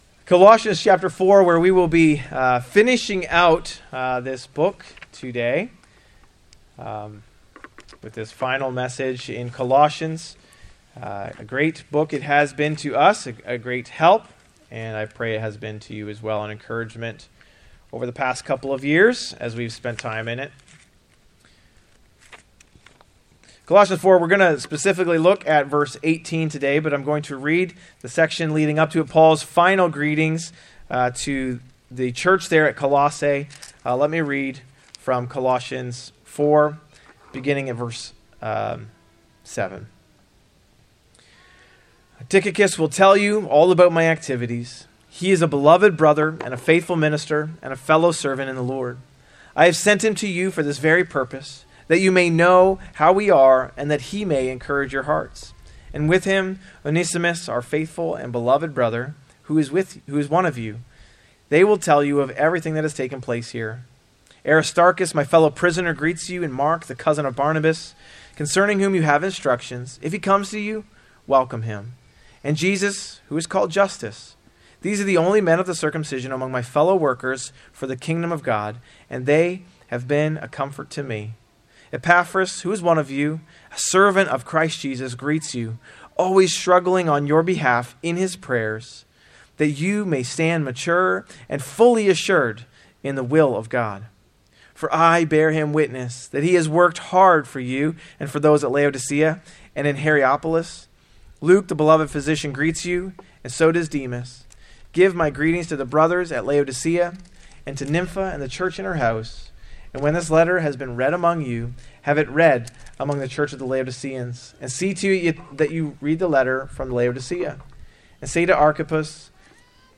A message from the series "In Christ Alone."